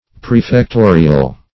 Prefectorial \Pre`fec*to"ri*al\, a. Of or pertaining to a prefect.